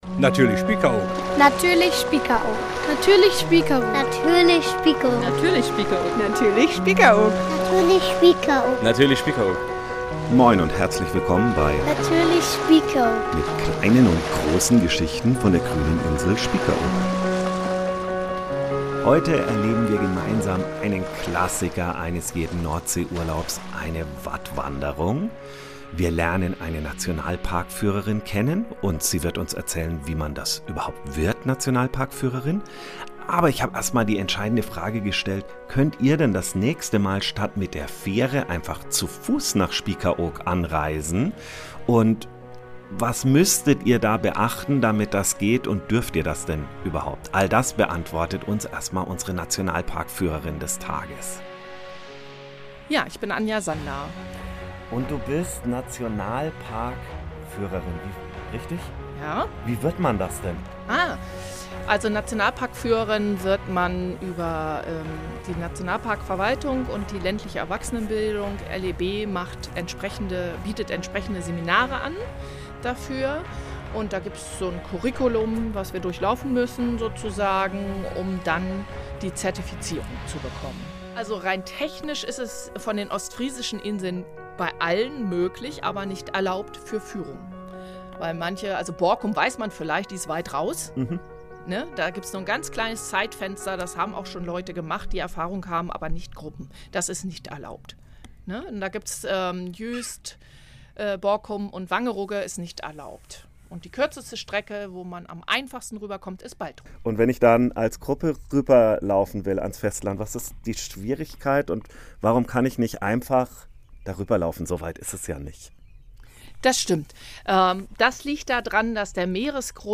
wir sind live dabei.